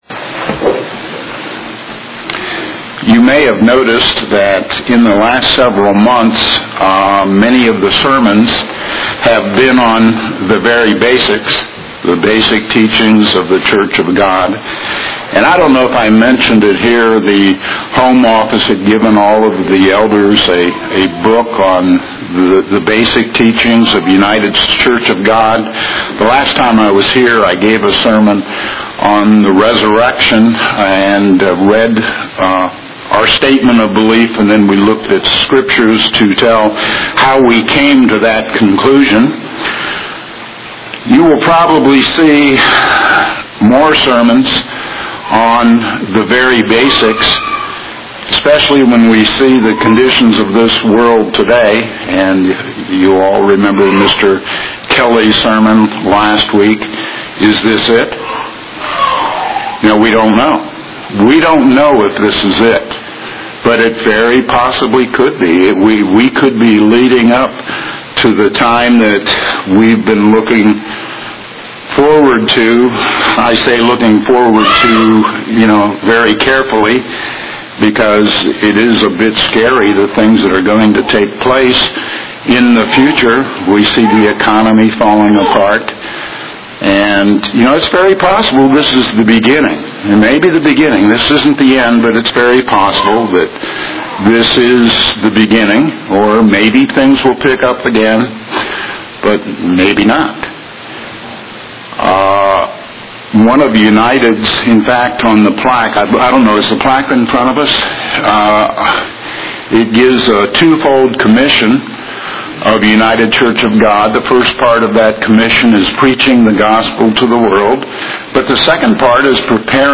Given in Greensboro, NC
UCG Sermon Studying the bible?